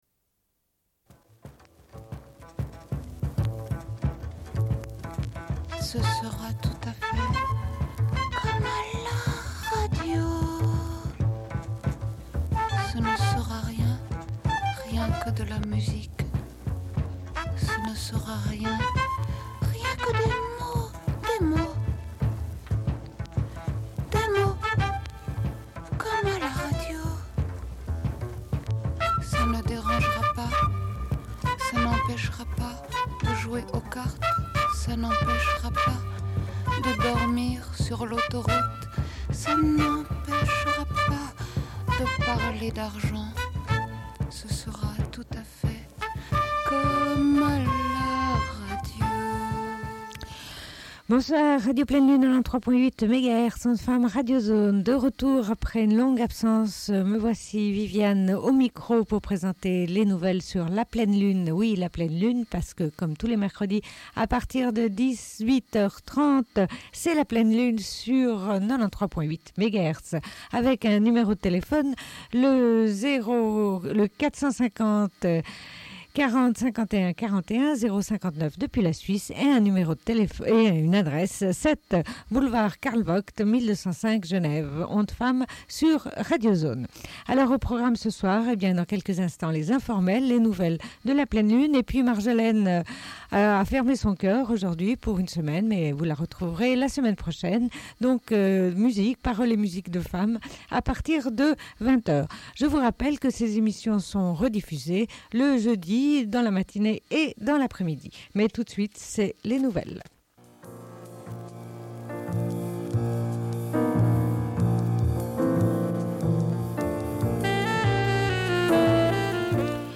Bulletin d'information de Radio Pleine Lune du 21.01.1998 - Archives contestataires
Une cassette audio, face B